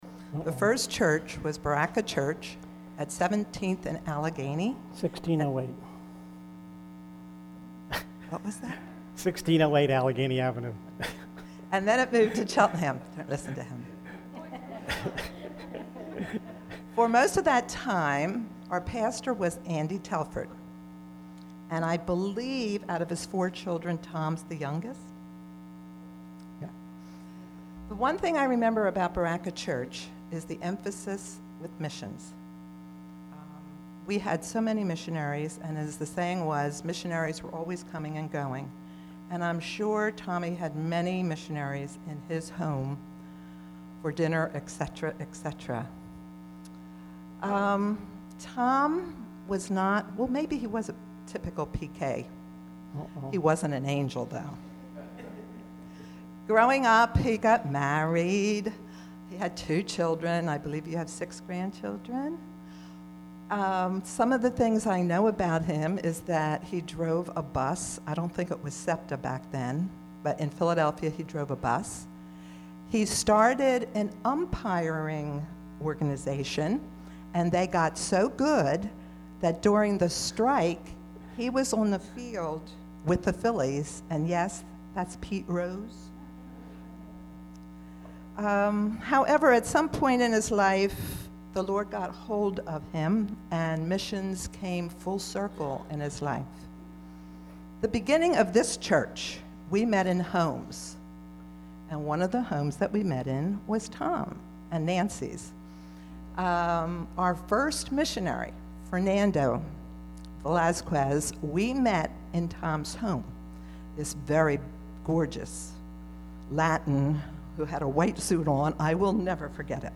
Mission Sunday 2009